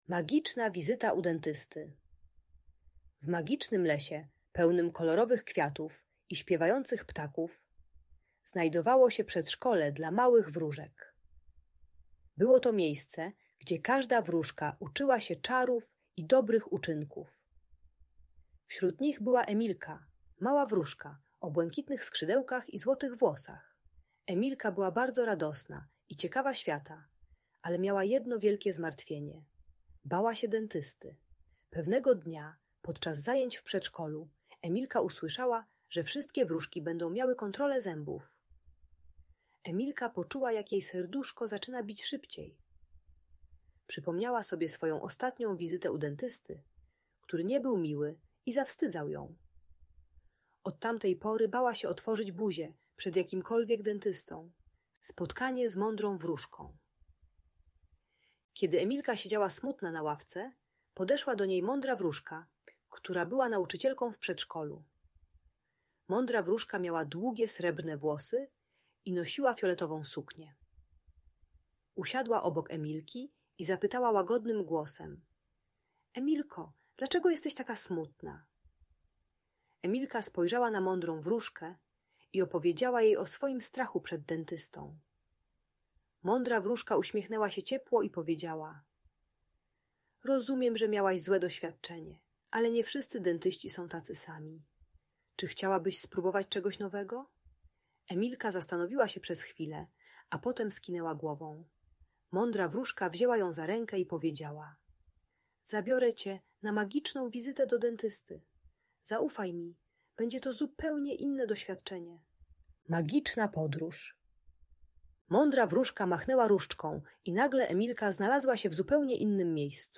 Magiczna Wizyta u Dentysty - Lęk wycofanie | Audiobajka